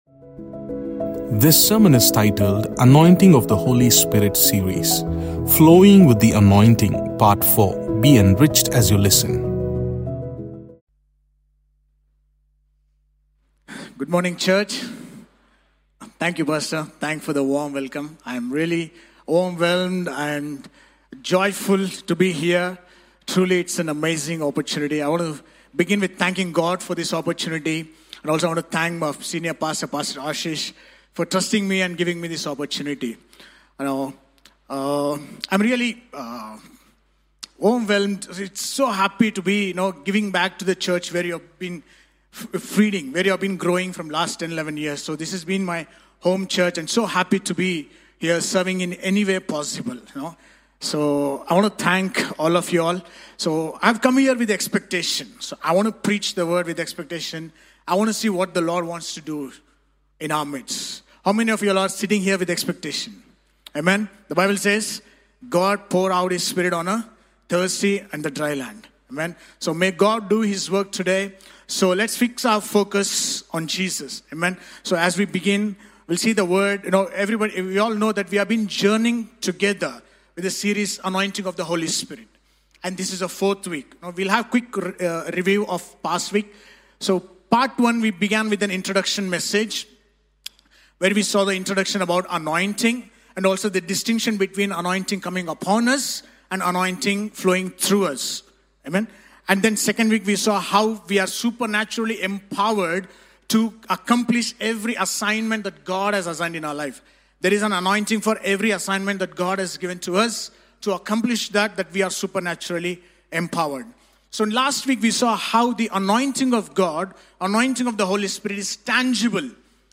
Sermons preached at All Peoples Church, Bangalore, India.